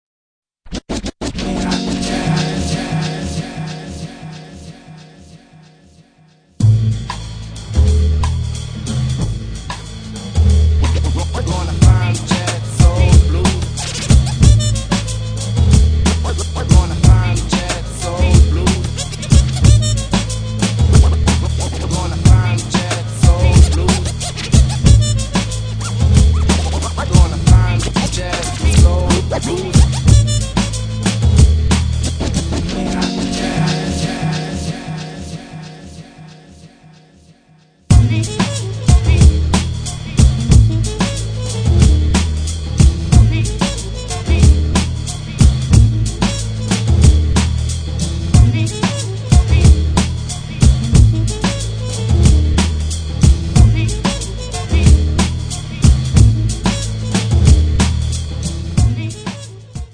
per poi perdersi in ostinati ipnotici